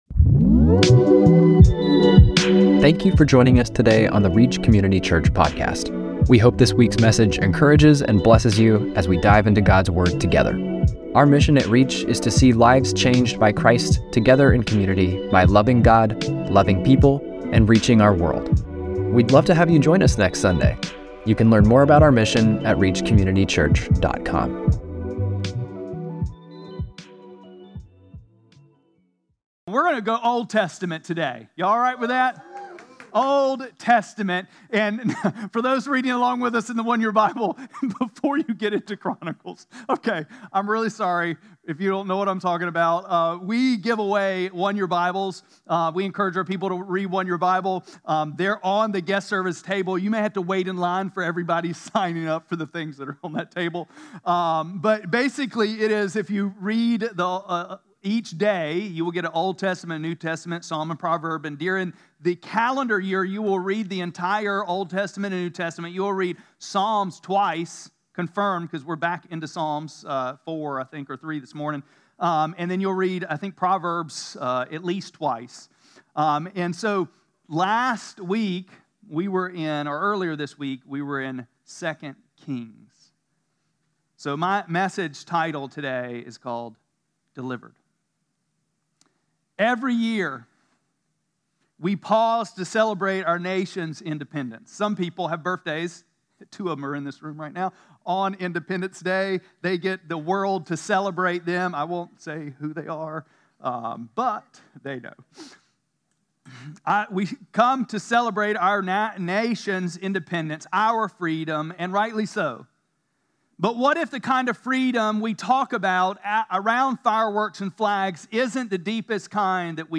7-6-25-Sermon.mp3